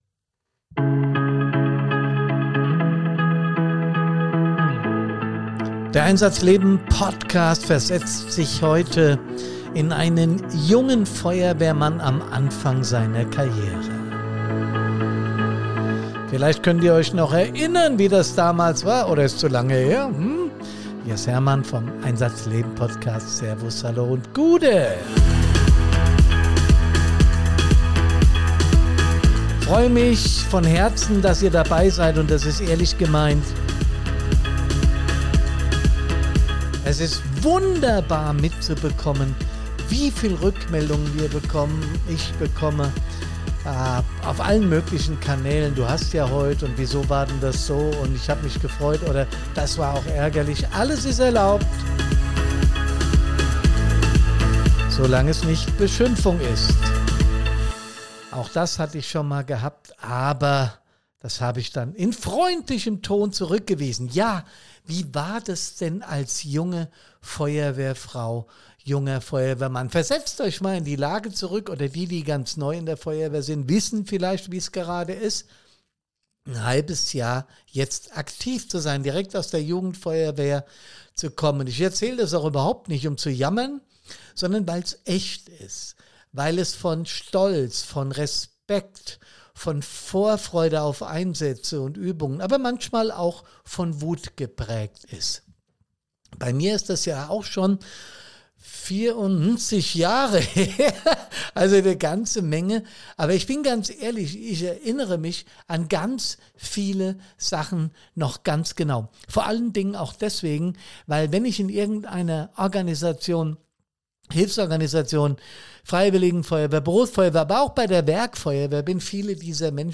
Ein Tagebuch.
Kein Gast, keine Fragen, kein Konzept.